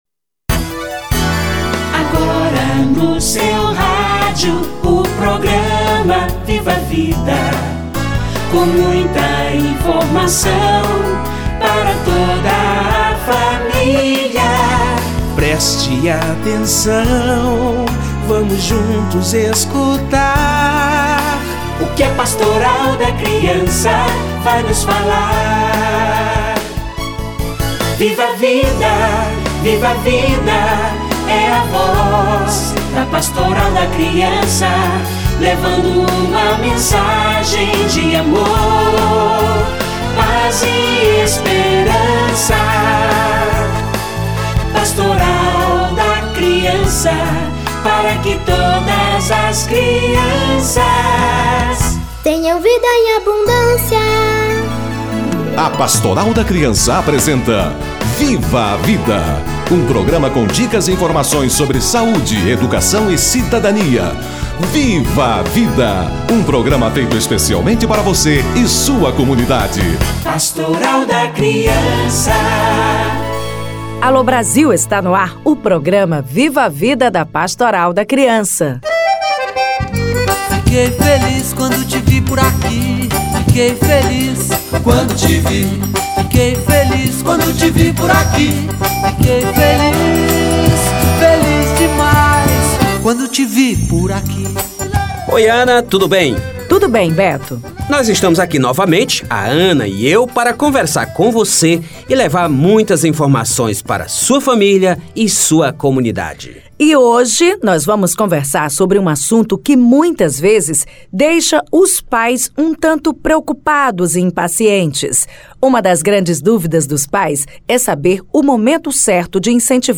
Controle dos esfíncteres - Entrevista